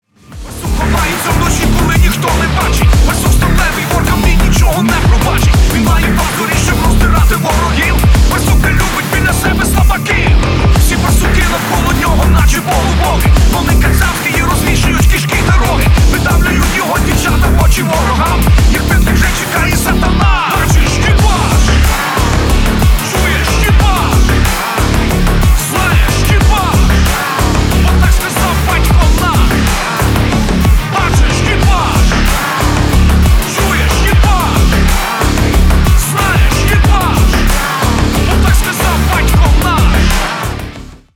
• Качество: 320, Stereo
мужской голос
громкие
украинский рок